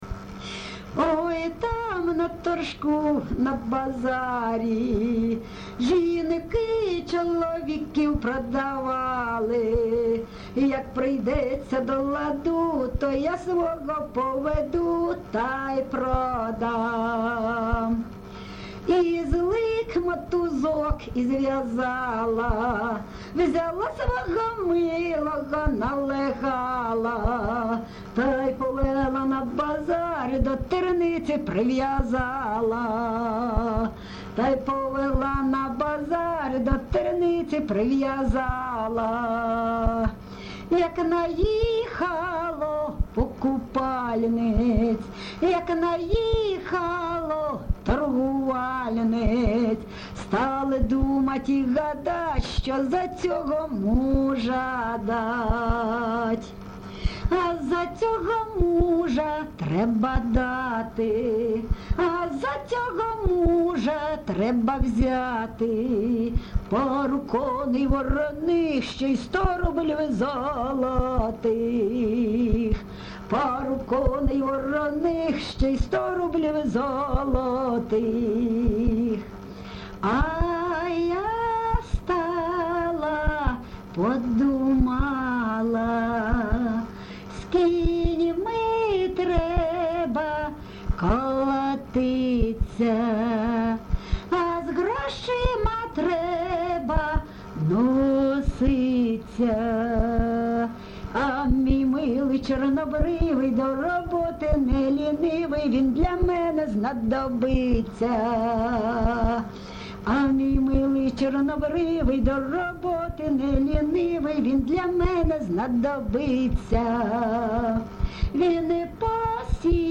ЖанрЖартівливі
Місце записус. Лозовівка, Старобільський район, Луганська обл., Україна, Слобожанщина